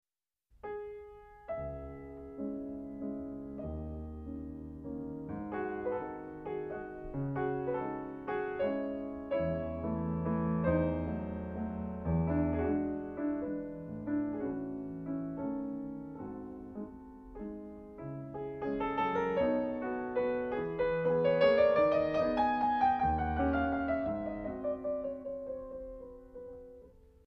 C sharp minor